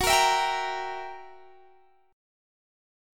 Listen to Gb7b9 strummed